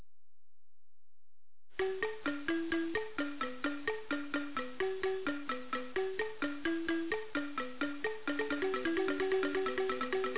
AMADINDA - das Xylophon aus Buganda
Die folgenden Klangbeispiele (Computersimulationen) zeigen am Lied Ssematimba ne Kikwabanga (Ssematimba und Kikwabanga), wodurch die Wahrnehmung der zweitönigen (und der dreitönigen) Melodie unterstützt oder gestört werden kann.